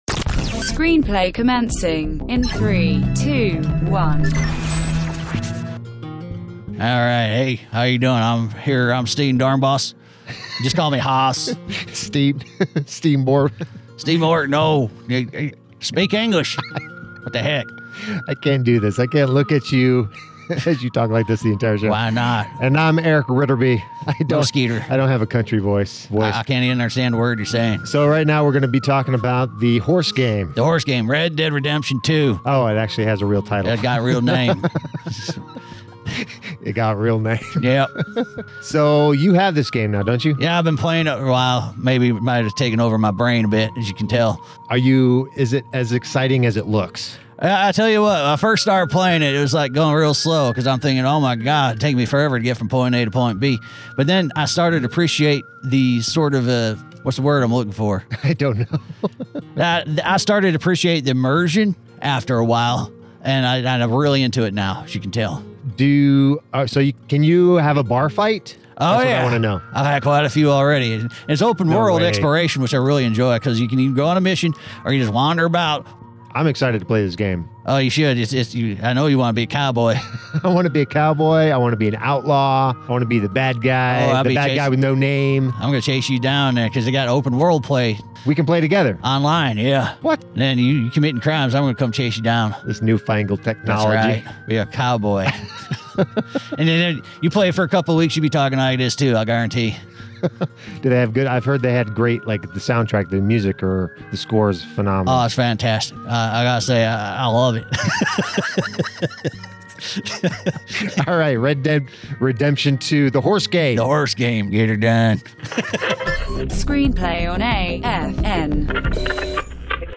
ScreenPlay is a 2 minute radio report regarding topics for anything on a screen, which includes movies, television, computers, and portable devices. The reporting format is an informal, engaged discussion between two or more broadcasters that still pushes awareness for venues and resources that Soldiers may take advantage of while overseas.